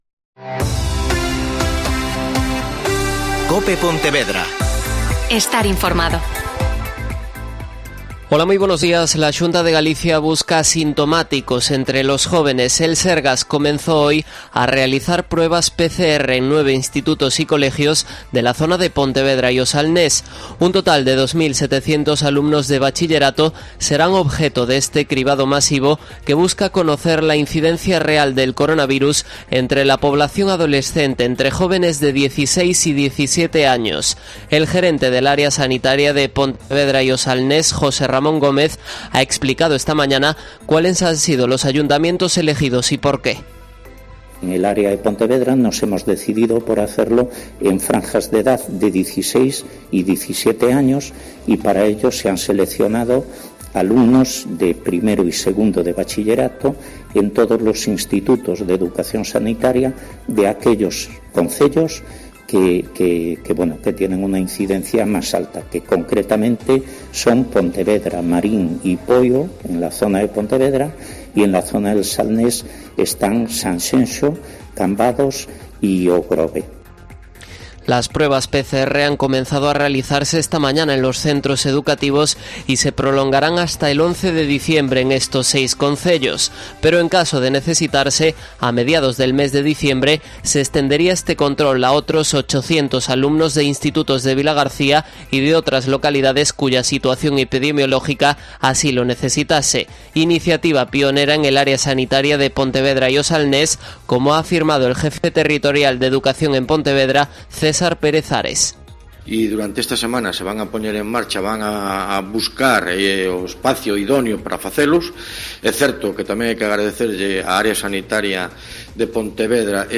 Mediodia en COPE Pontevedra (Informativo 14:20h)